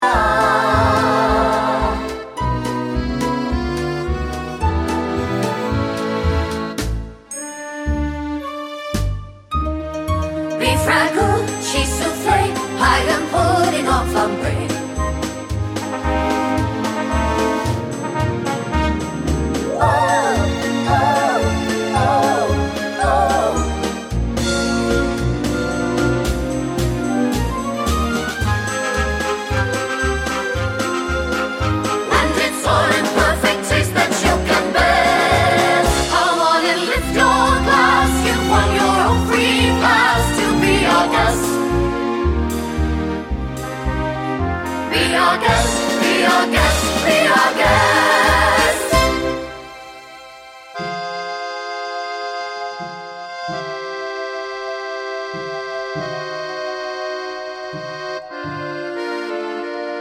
no Backing Vocals Soundtracks 3:43 Buy £1.50